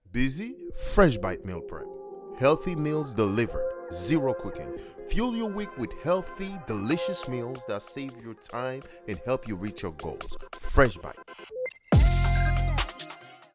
Online Ad Freshbite: Upbeat, Motivational, Warm, energetic voice that conveys excitement